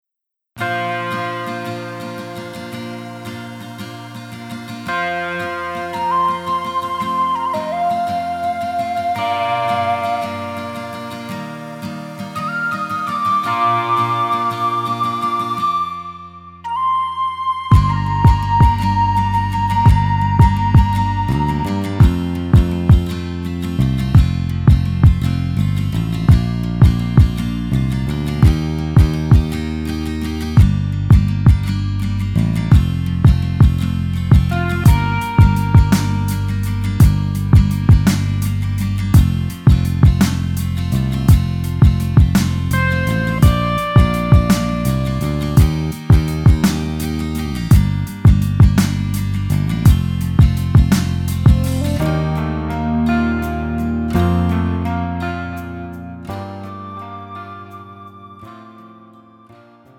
음정 -1키 3:21
장르 가요 구분 Pro MR